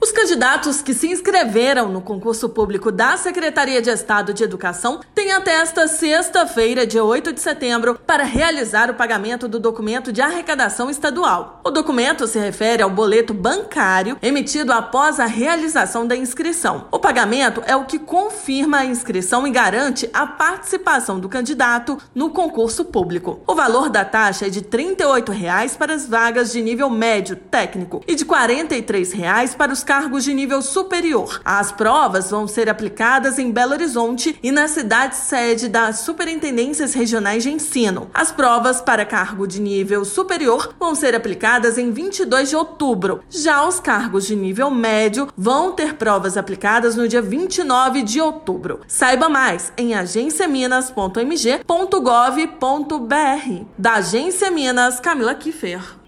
Os candidatos que se inscreveram no concurso público da Secretaria de Estado de Educação (SEE/MG), referente ao Edital Seplag/SEE nº 3/2023, têm até sexta-feira (8/9) para realizar o pagamento do Documento de Arrecadação Estadual (DAE) — boleto bancário emitido após a realização da inscrição. Ouça matéria de rádio.